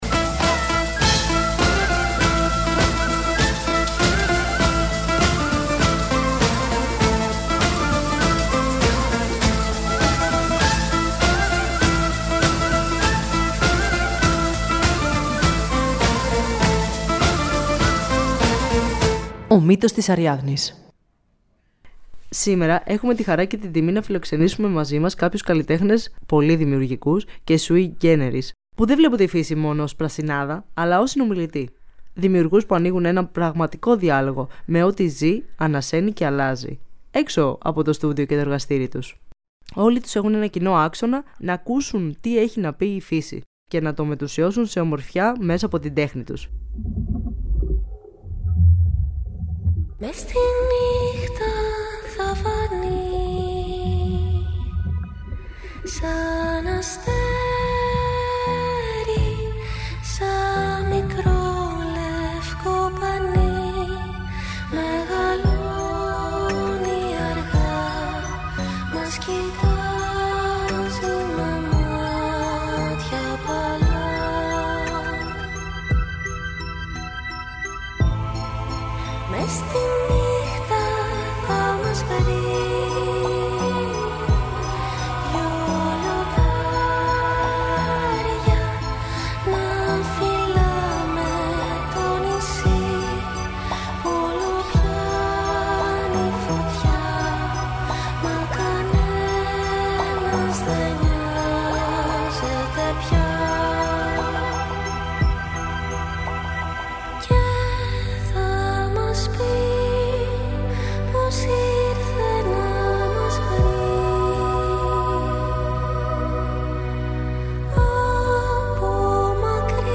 Συνομιλούμε με τρεις καλλιτέχνες που δεν βλέπουν τη φύση μόνο ως πρασινάδα, αλλά ανοίγουν έναν πραγματικό διάλογο με ό,τι ζει, ανασαίνει και αλλάζει έξω από το στούντιο και το εργαστήρι τους.